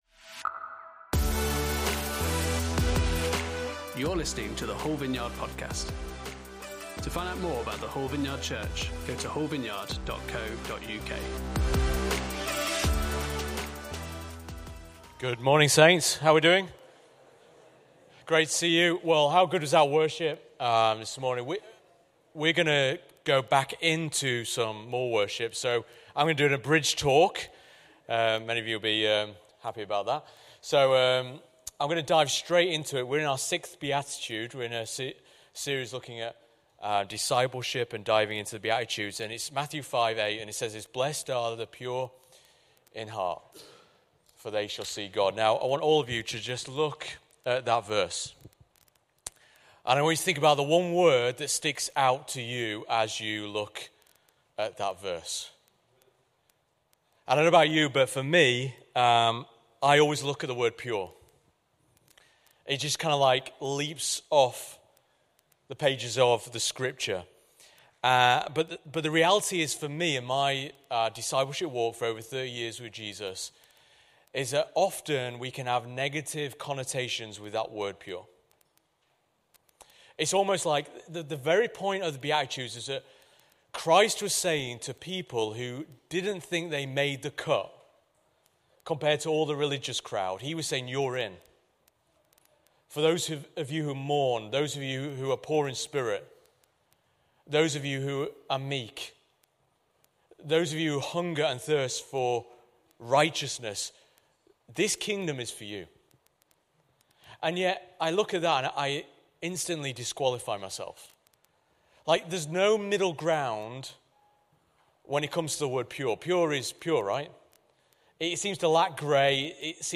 Series: Discipleship: The Beatitudes Service Type: Sunday Service